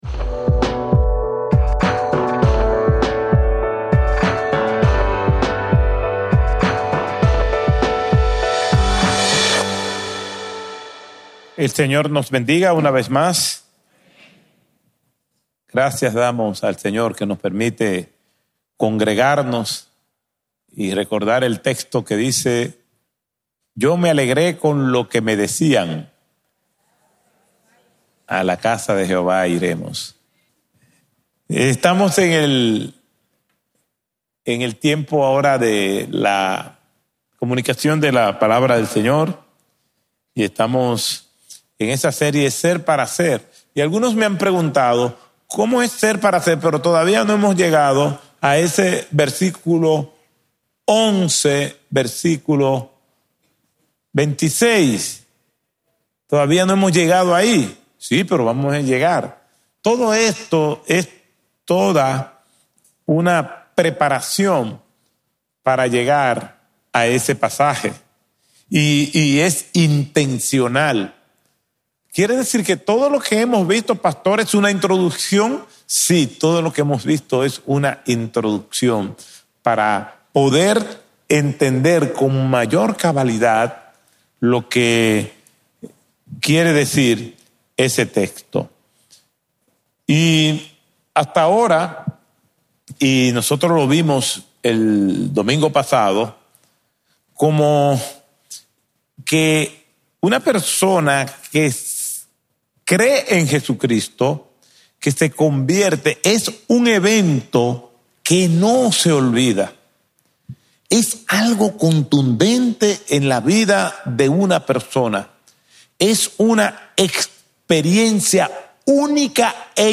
Un mensaje de la serie "Ser para hacer."